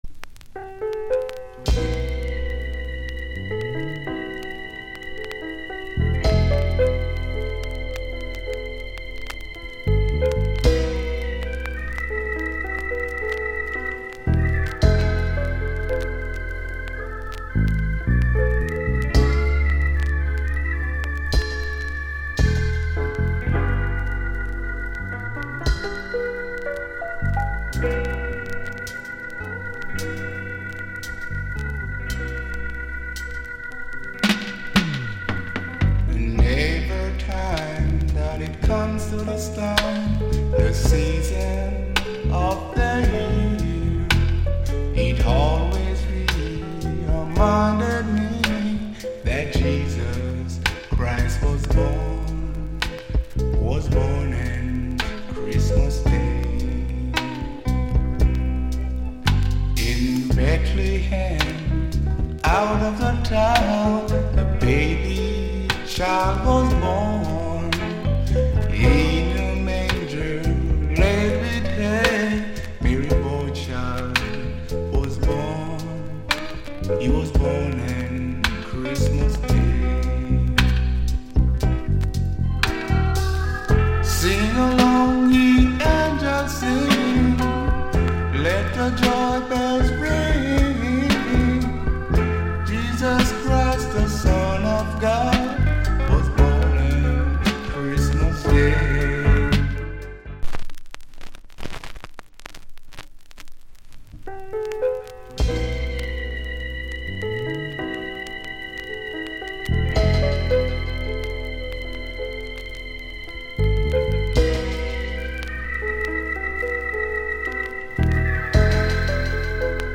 * まったりとしたバラッド。